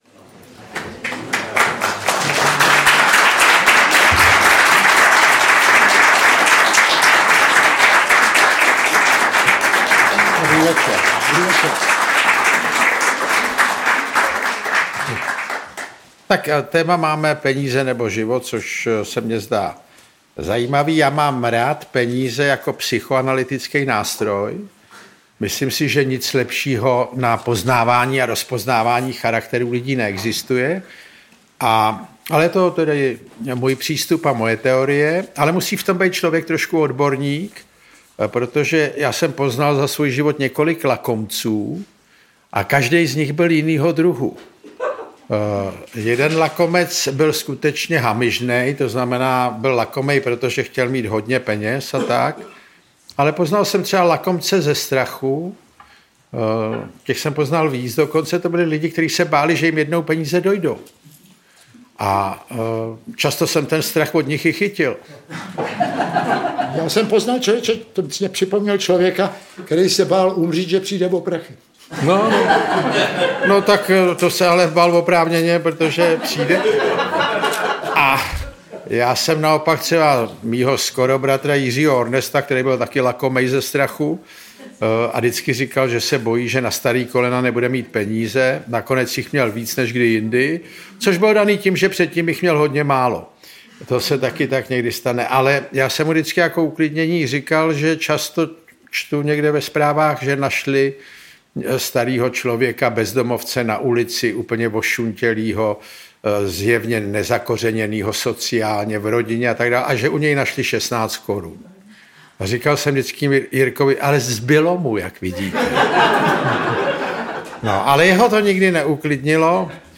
Peníze, nebo život!: Originální a autentické autorské čtení z knih Ivana Krause
Interpreti:  Ivan Kraus, Jan Kraus
Bratři Krausové uvažují, zda by za větou „Peníze, nebo život!“ neměl být spíše otazník. Originální a autentické autorské čtení z knih Ivana Krause doplňuje řada vtipných glos a poznámek. Záznam představení z pražské Violy.